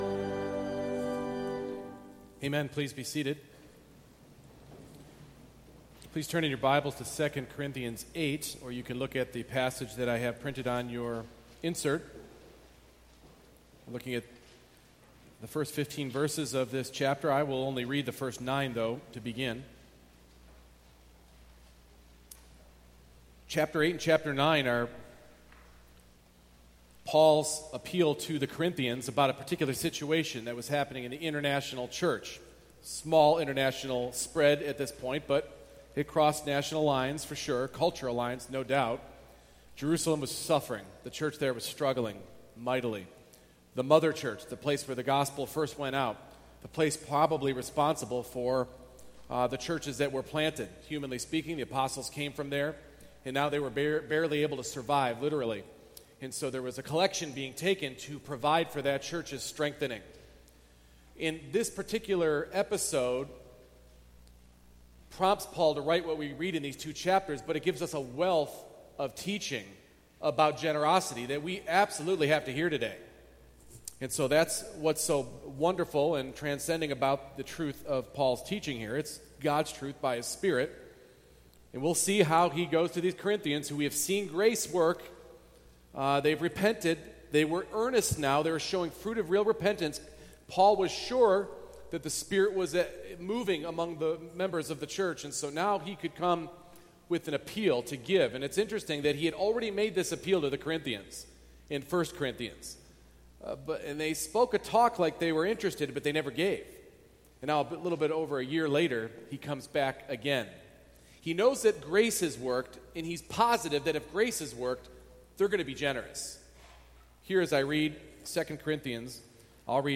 2 Corinthians 8:1-15 Service Type: Morning Worship Apprehension of God’s grace produces generosity.